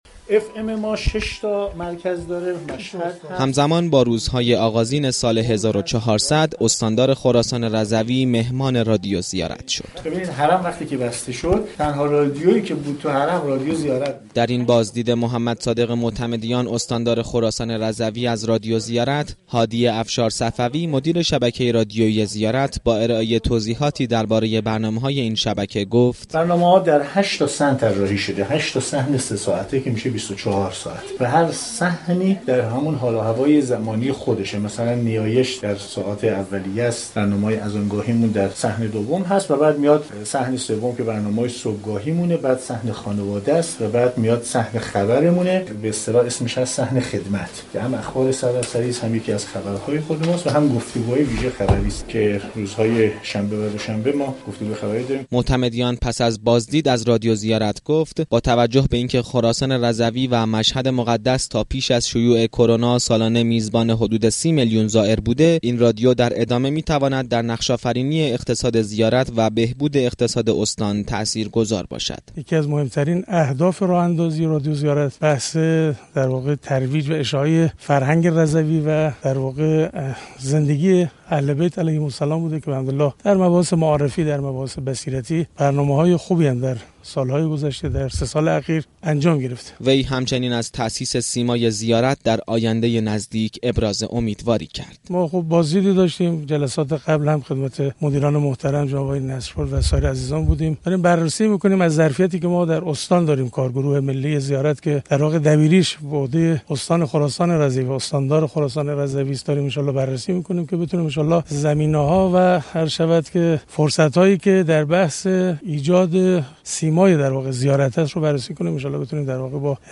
استاندار خراسان رضوی در بازدید از رادیو زیارت از پیگیری راه‌اندازی سیمای زیارت در مشهد مقدس خبر داد.